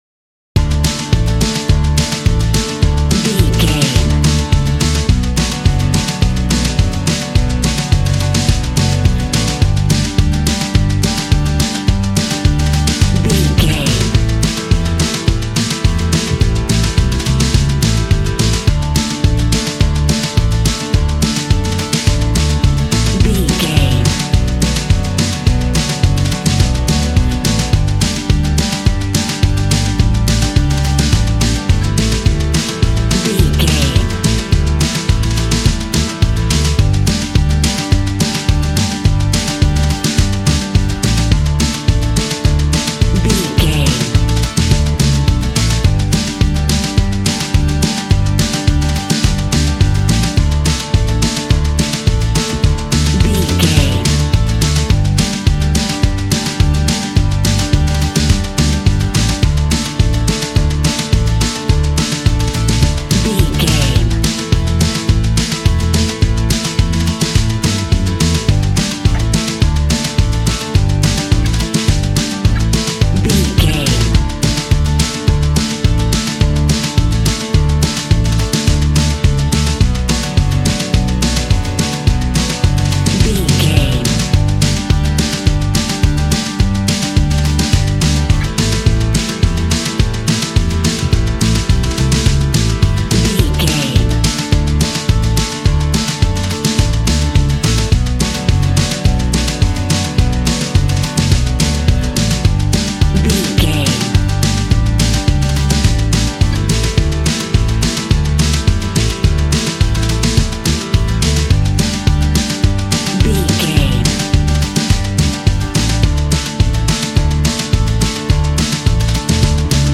A fast and uptempo piece of banjo driven country music.
Ionian/Major
D
fun
bouncy
positive
double bass
drums
acoustic guitar